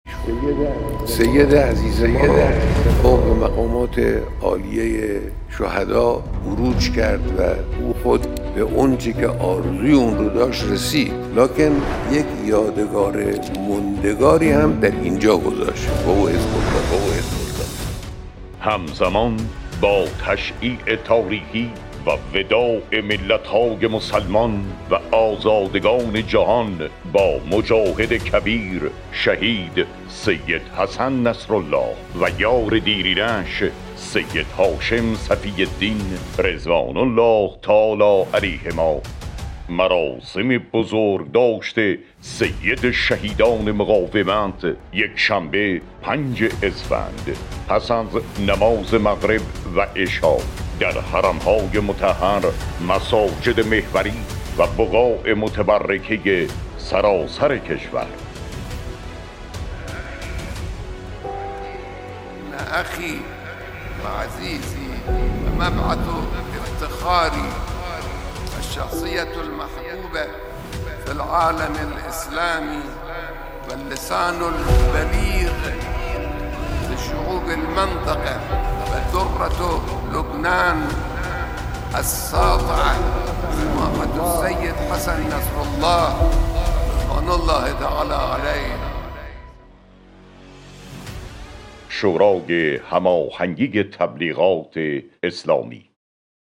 تیزر اطلاع رسانی مراسم بزرگداشت شهیدان سیدحسن نصرالله و سید هاشم صفی الدین